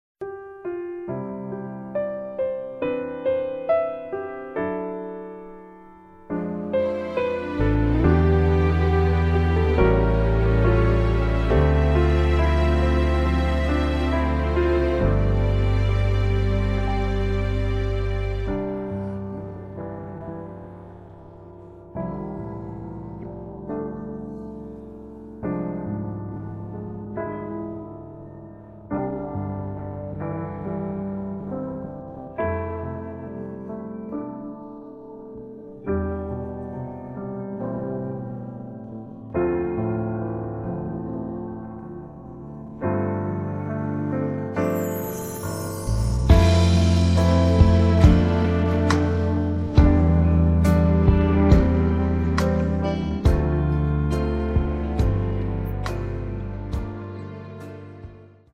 음정 원키 4:11
장르 가요 구분 Voice Cut